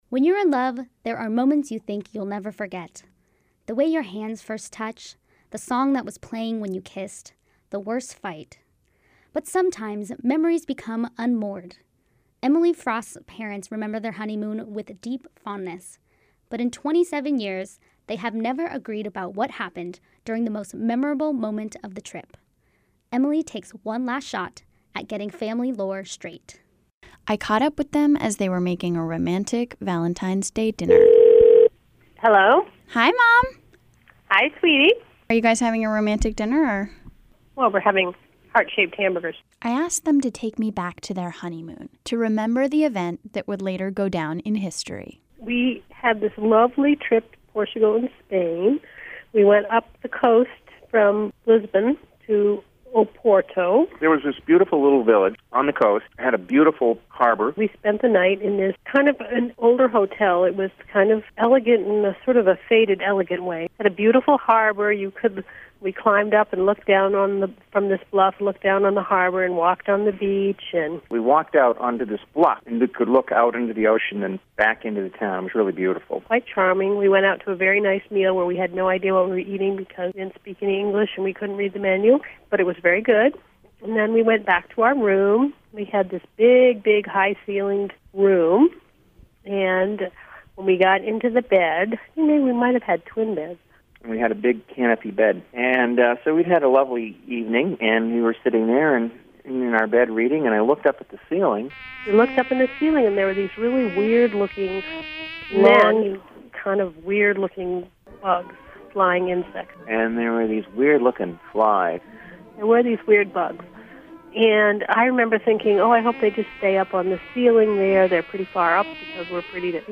Detours is an offbeat podcast with creative, boundary-pushing audio.